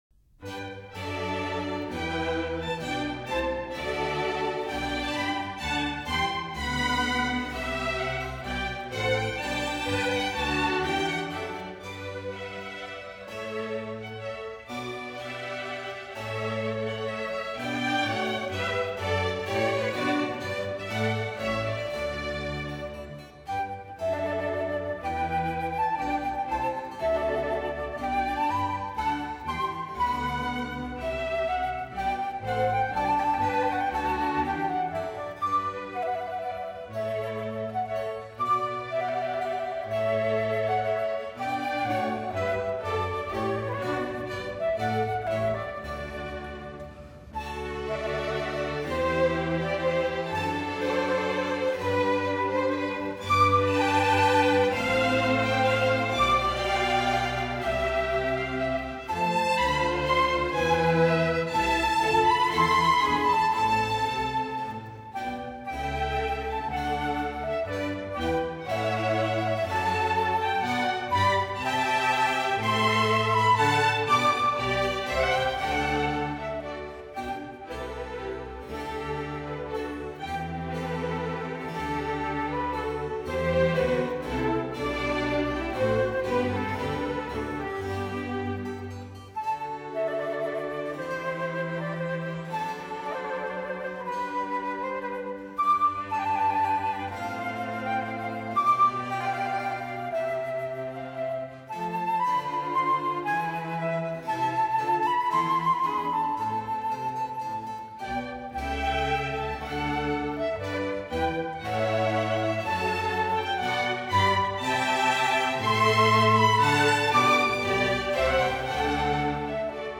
小步舞曲和三声中部
小步舞曲是一种源于法国民间的三拍子舞曲，17世纪传入宫廷，它的速度徐缓、风格典雅。